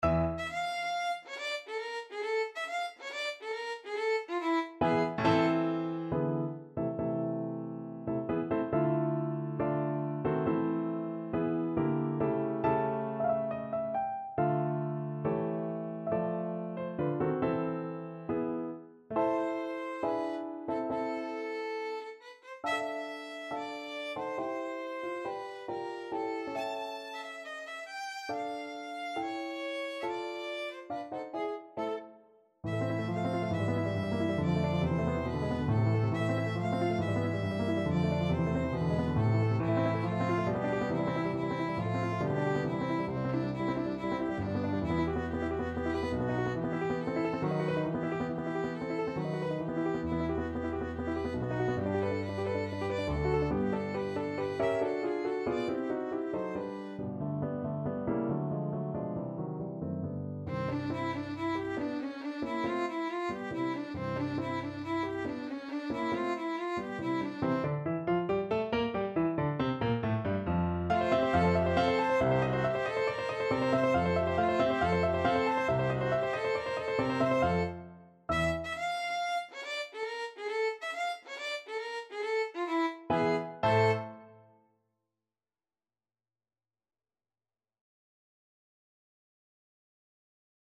Violin version
4/4 (View more 4/4 Music)